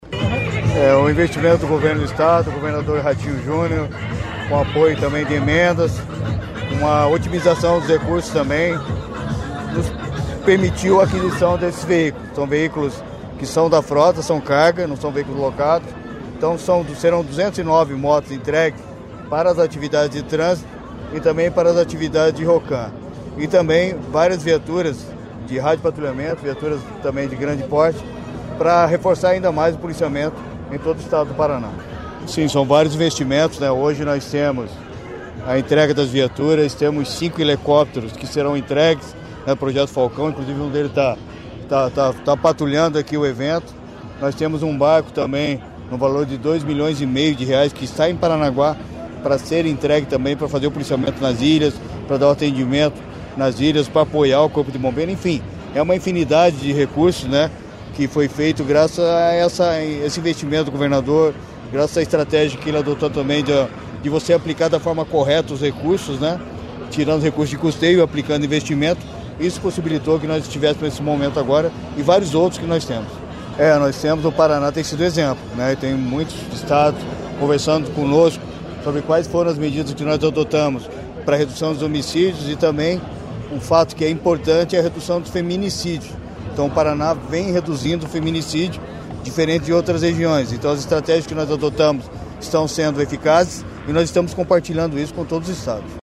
Sonora do secretário Estadual da Segurança Pública, Hudson Teixeira, sobre a entrega de veículos para a PM do Paraná